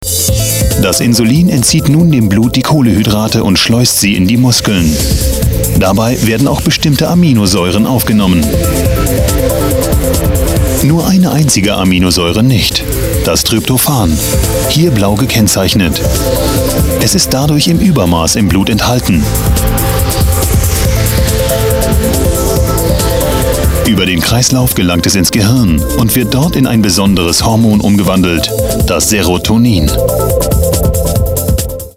Kein Dialekt
Sprechprobe: Industrie (Muttersprache):
german voice over artist.